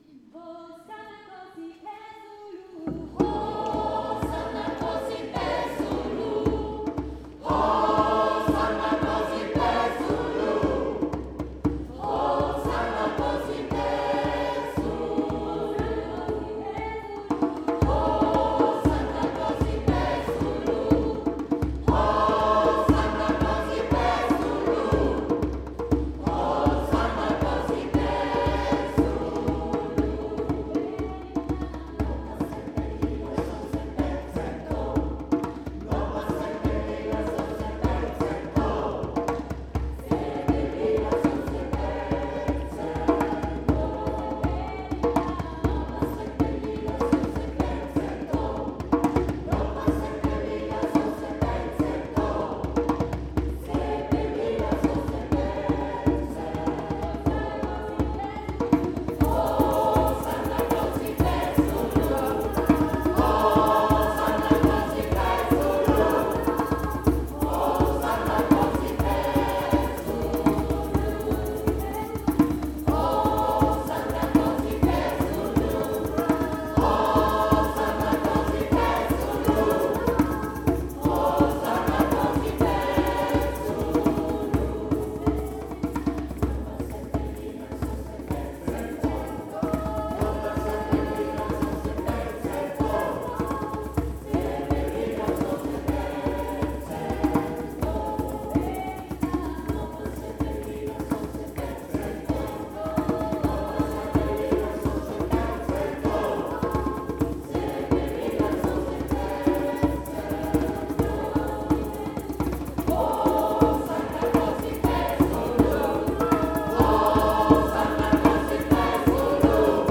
Mit rhythmischen Bewegungen zu afrikanischen Liedern begeisterte der Chor unserer Pfarre
Die afrikanischen Lieder aus dem Gottesdienst